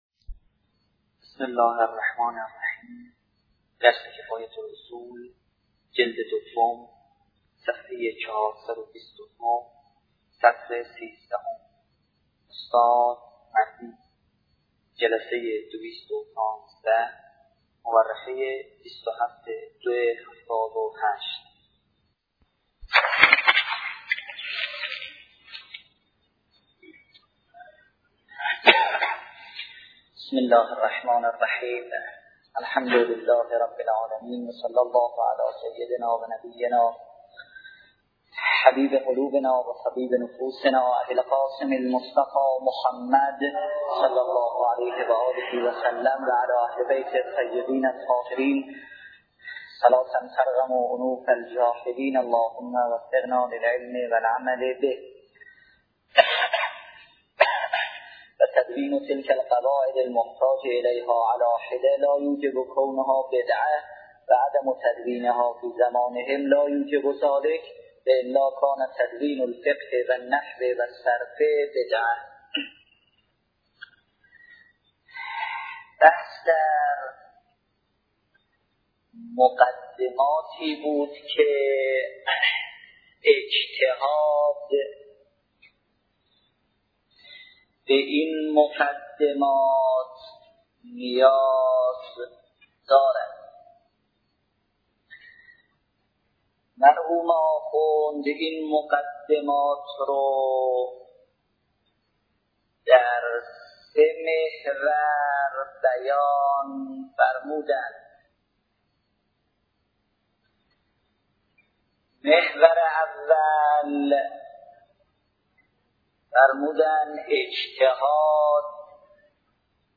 نیوایج